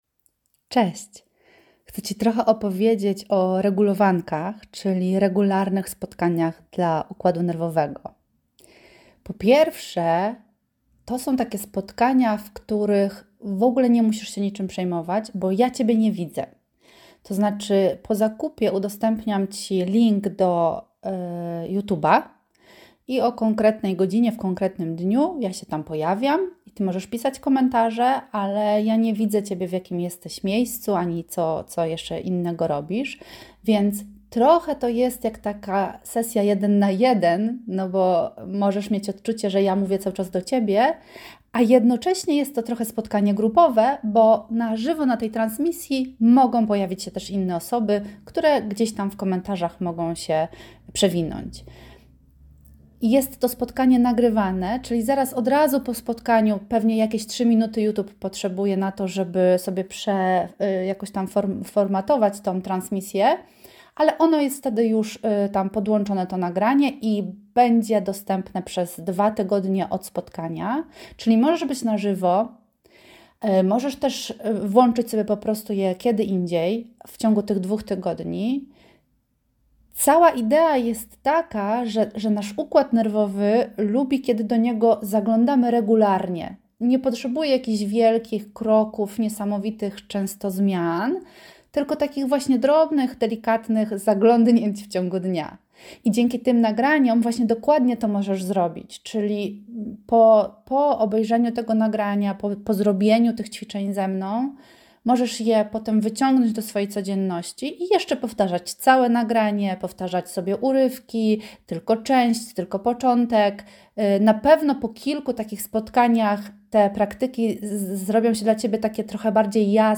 Posłuchaj, jak opowiadam, co to za spotkania, jak wyglądają i czego możesz się spodziewać.